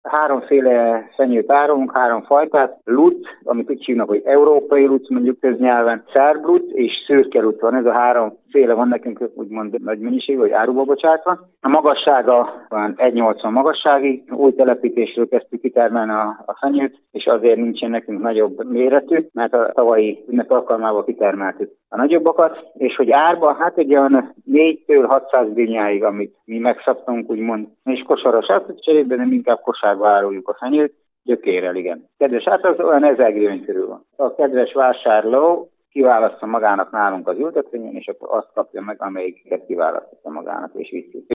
fa_riport.mp3